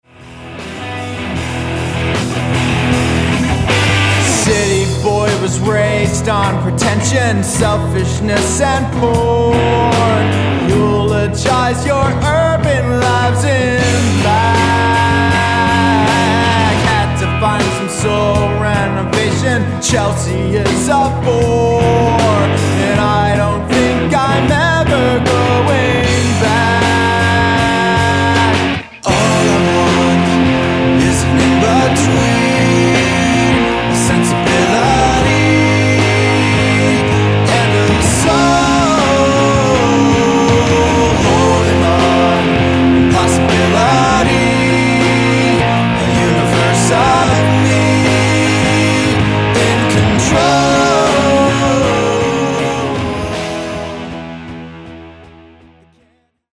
rock album